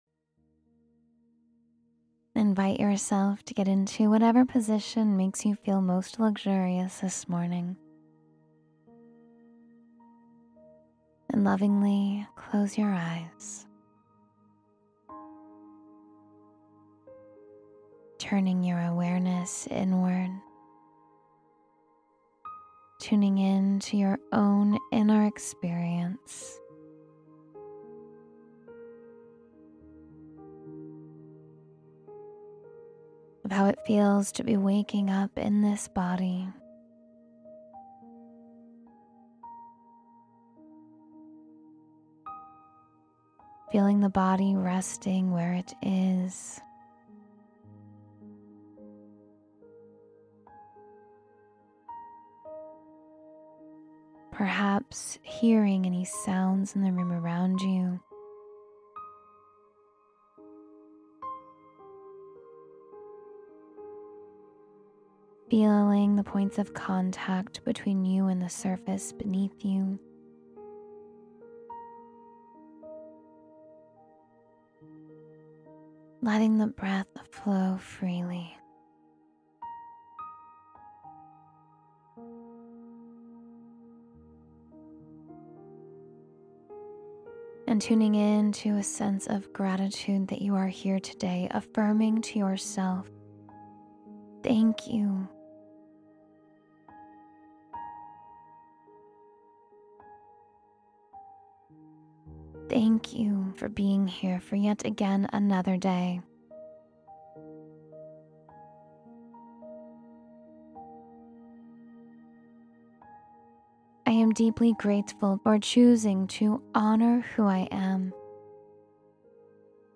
Manifest a Life of Wealth and Ease — Meditation for Manifestation and Ease